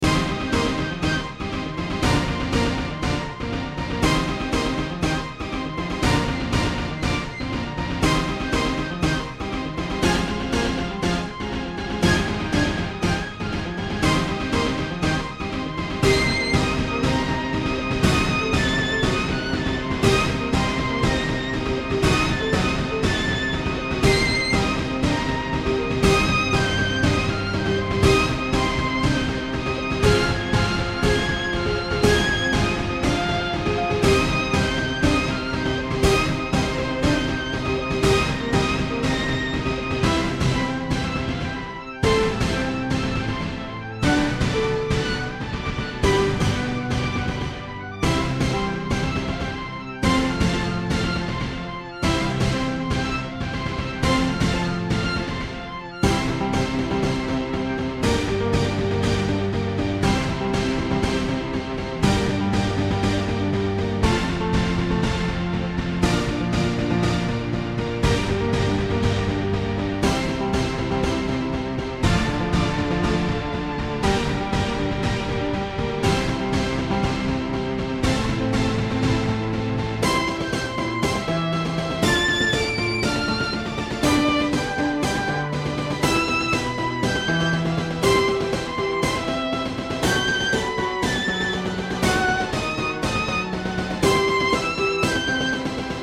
バイオリン、ピアノ、ビオラ、オーケストラ ヒット